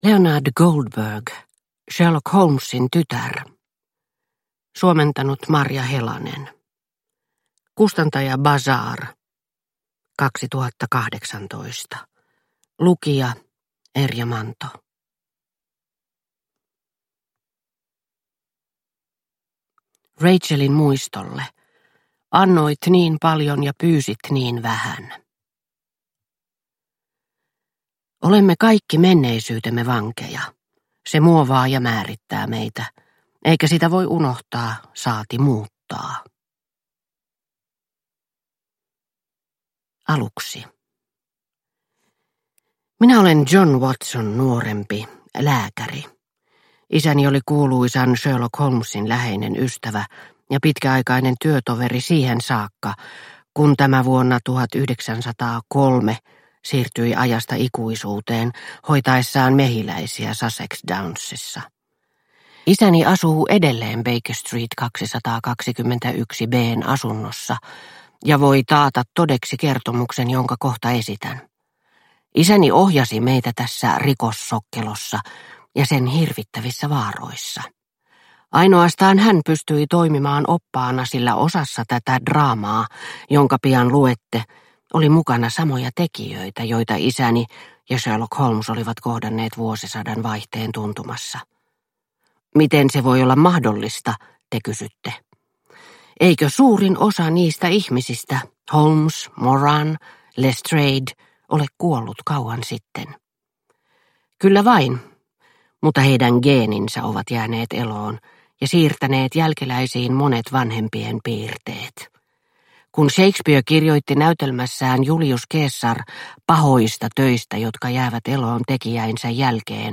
Sherlock Holmesin tytär – Ljudbok – Laddas ner